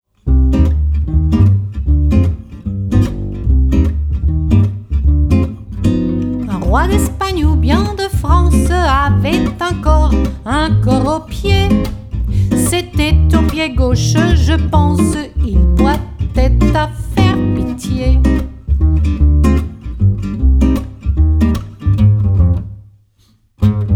stimme, ukulele
gitarre, percussion, melodika und stimme
bass, gitarre, stimme
mellotron, rhodes